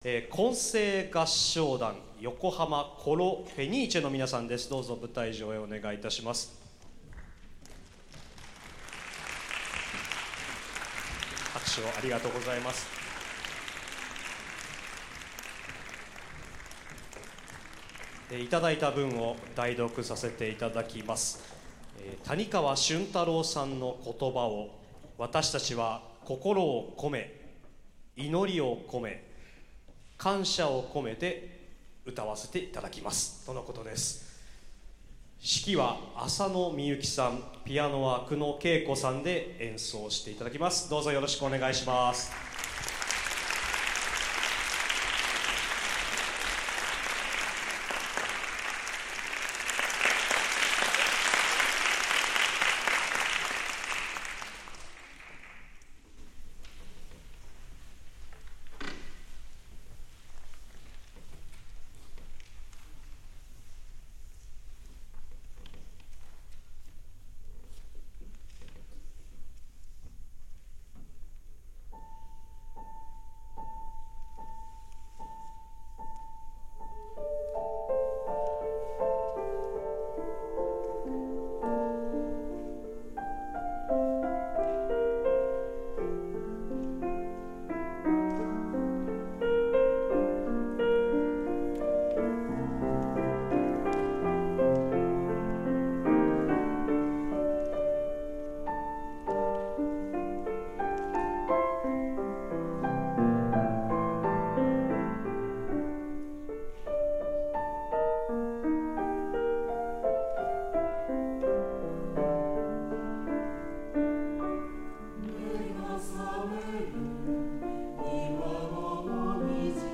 fenice_symbol 混声合唱団
○場所：神奈川県立音楽堂
◆混声合唱とピアノのための「感謝」  谷川俊太郎 詩/松下耕 作曲
[実況録音(.mp3)]
繊細で音楽的な演奏でした。
芯のある発声が求められます。
ピッチの安定感を高めてください。